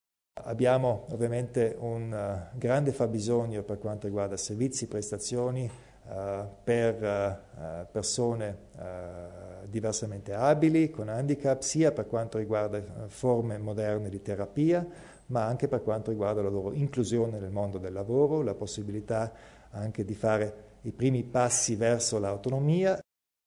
Il Presidente Kompatscher spiega il progetto denominato agricoltura sociale